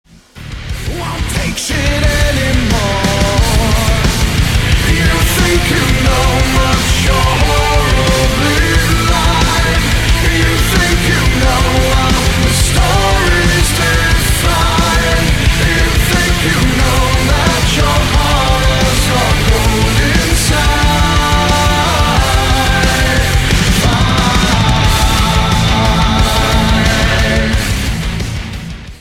• Качество: 192, Stereo
Metal